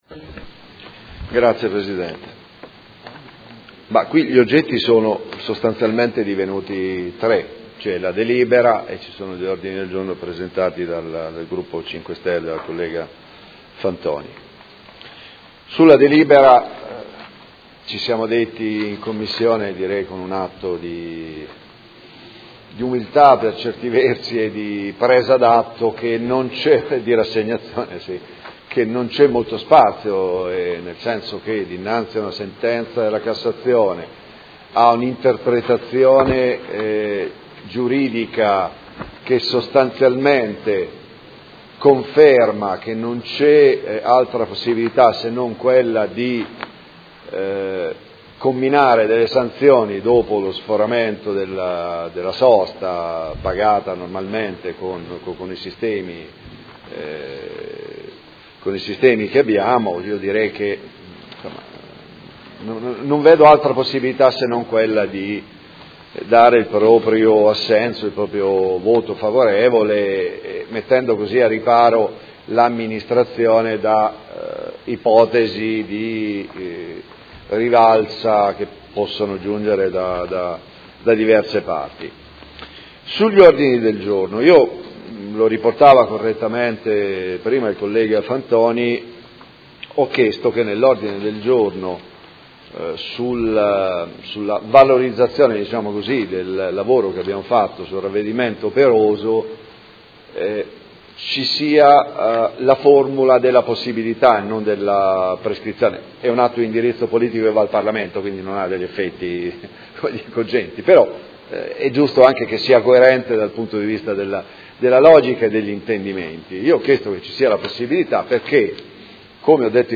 Paolo Trande — Sito Audio Consiglio Comunale
Seduta del 18/05/2017. Dibattito su Proposta di Deliberazione e Ordini del Giorno inerenti aree di parcheggio a pagamento su strada, adeguamento colonnine parcheggi e introduzione del ravvedimento operoso nel codice della strada